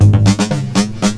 DRUMFILL05-R.wav